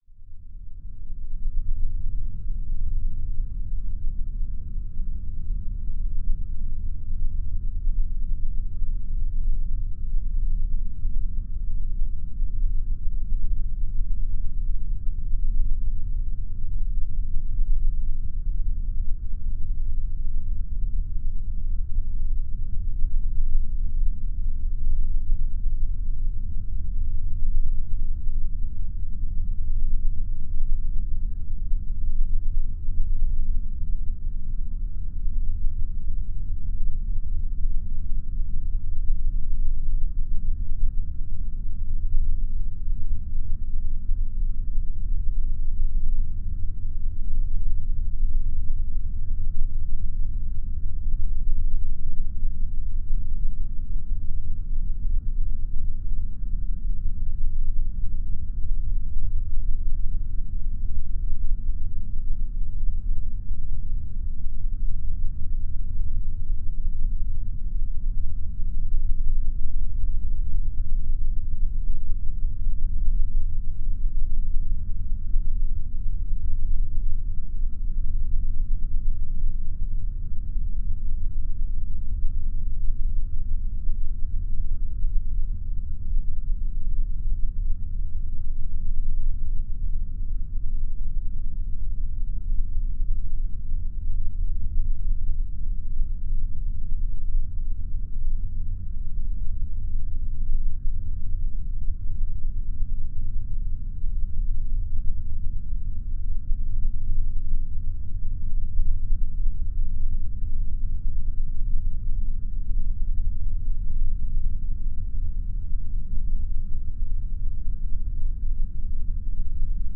Здесь собраны реалистичные аудиоэффекты: скрип половиц, завывание ветра в пустых комнатах, отдаленные голоса и другие жуткие детали.
Звук жуткой тишины в покинутом пустом доме